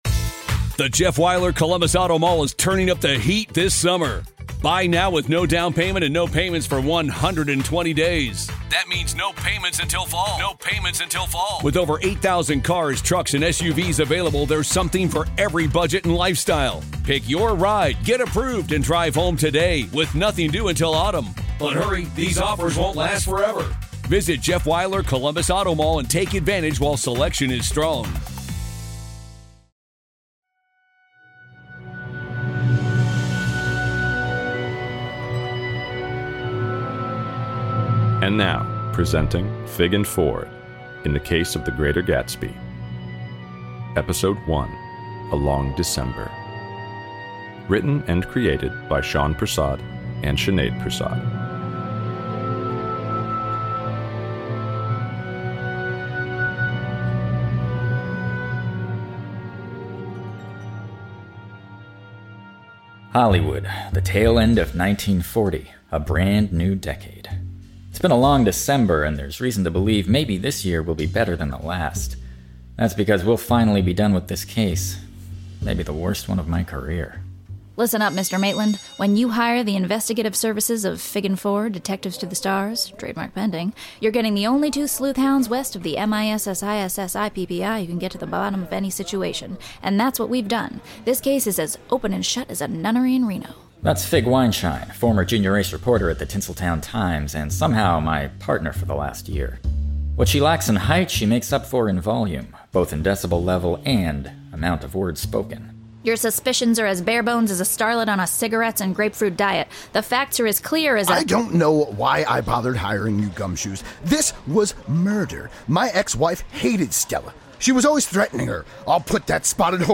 Audio recording by Ears Up Studio